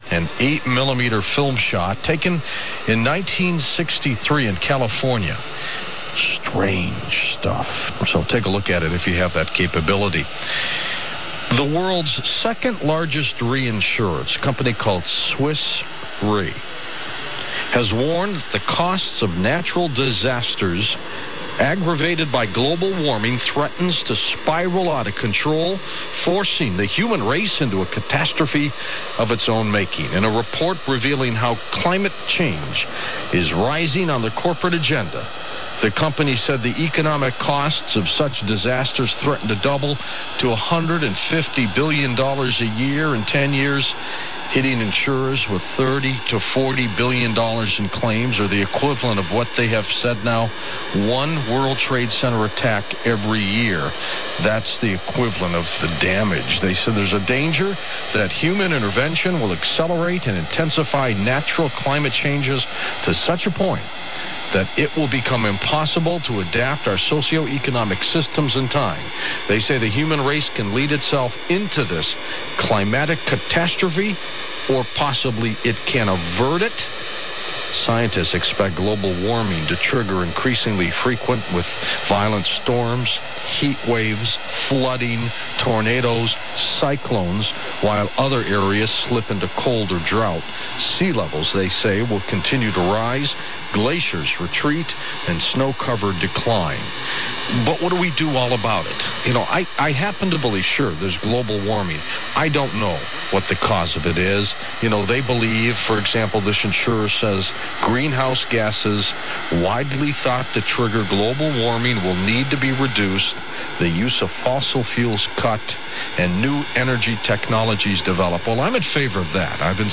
(IBOC on from Las Vegas)
You can clearly hear the effects of the skywave/selective fading on the hash.
(That undulating swishy sound.)